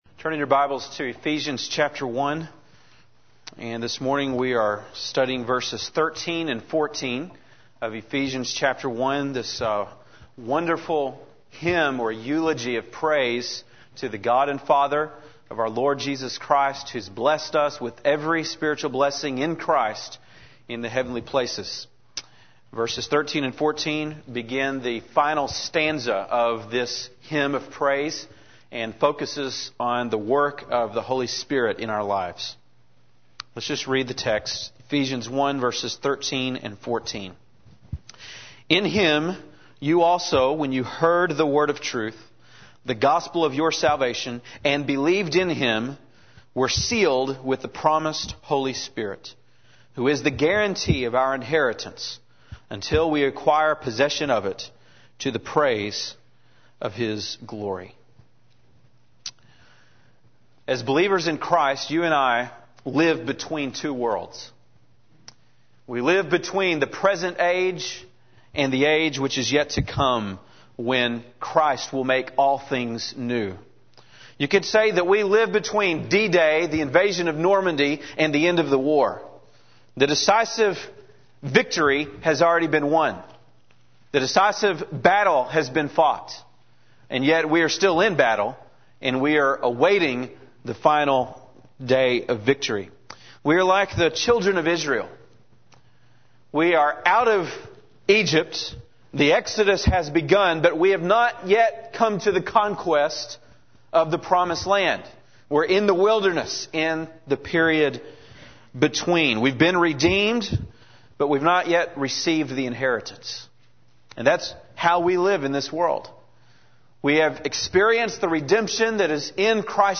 September 12, 2004 (Sunday Morning)